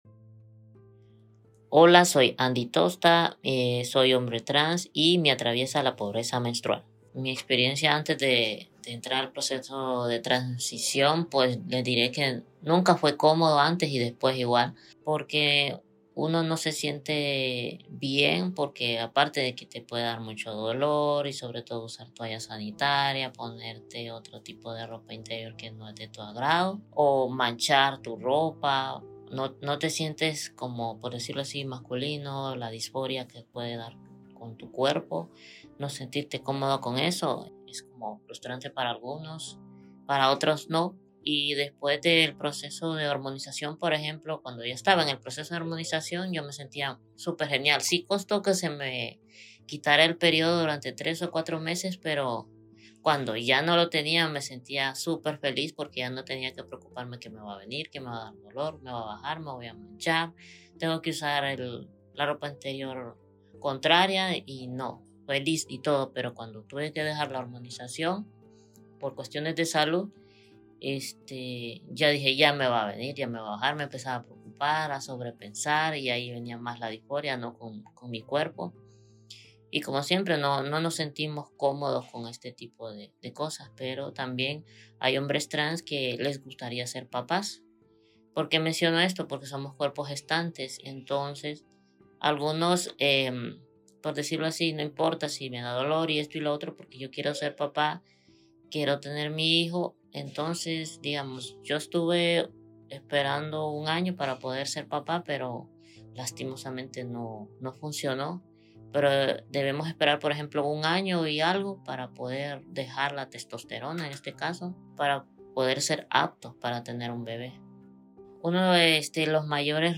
un hombre trans que ha vivido los desafíos cotidianos de la pobreza menstrual en Honduras.